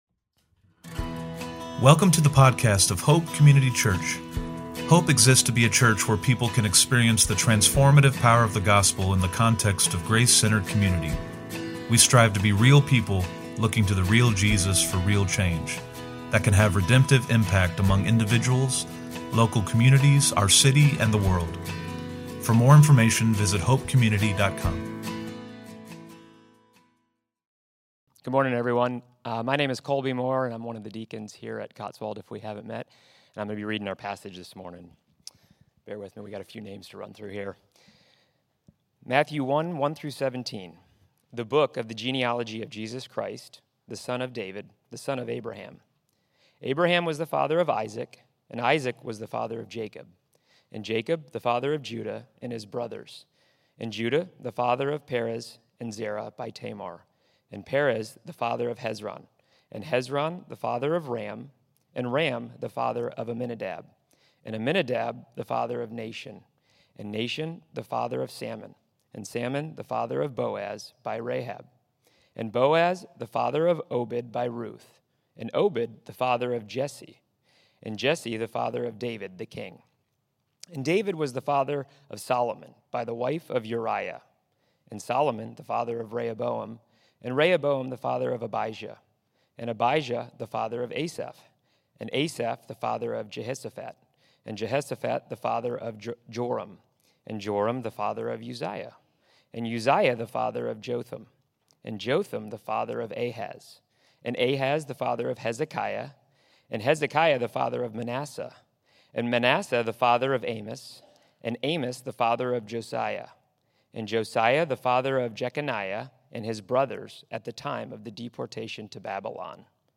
Sermons & Studies - Hope Community Church
From Location: "Cotswold"